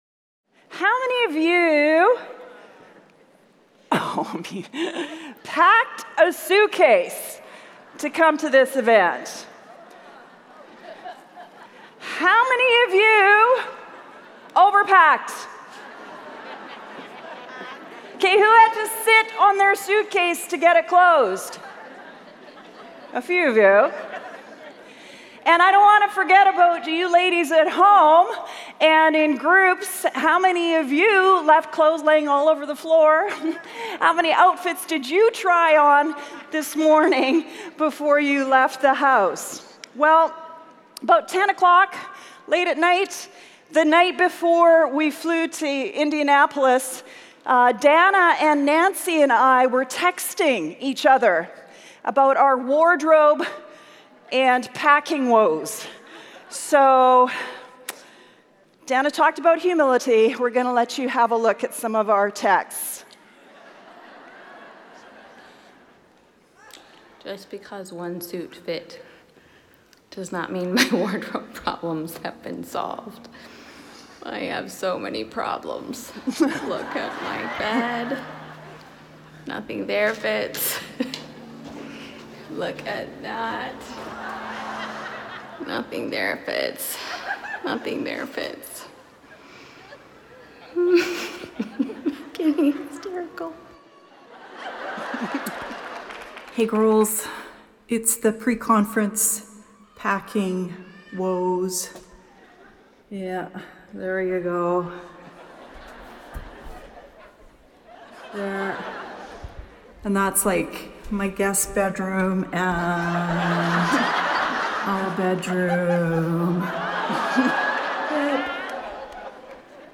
Ditch the Baggage: Marks of Genuine Repentance | Revive '19 | Events | Revive Our Hearts